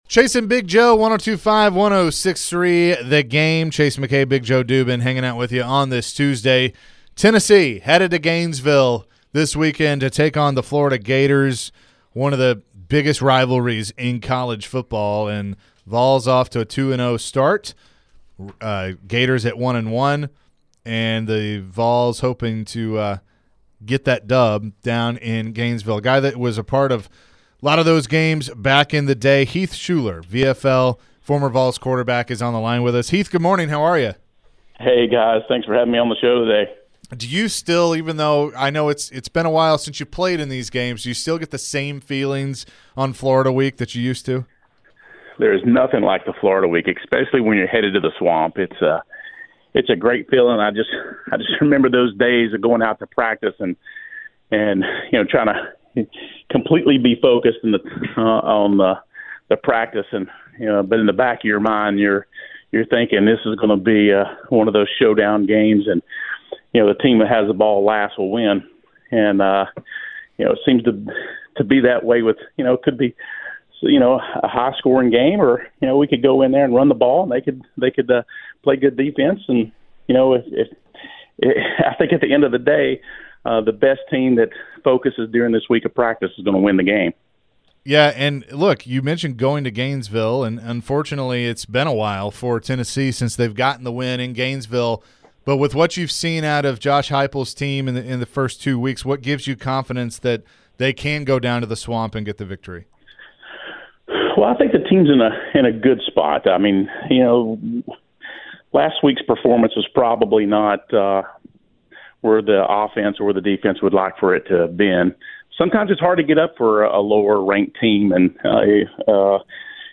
Florida or Tennessee? later in the interview